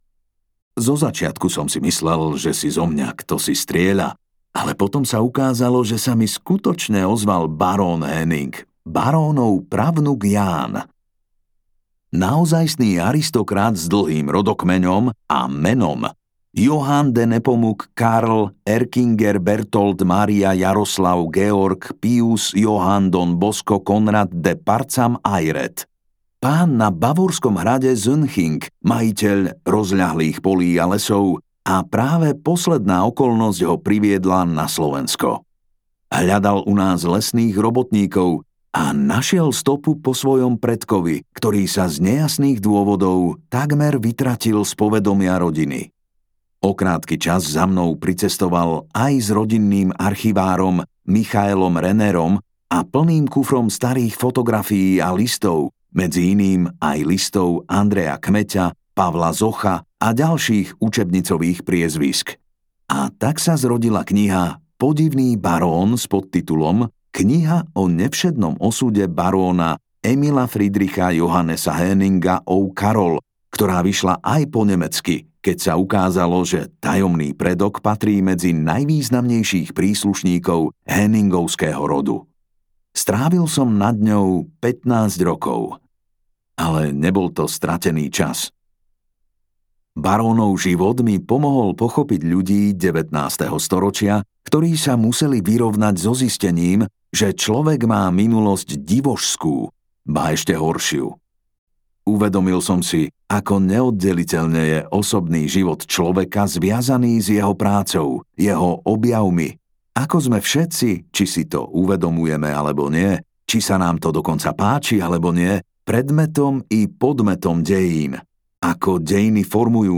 Stopy dávnej minulosti - Slovensko v praveku audiokniha
Ukázka z knihy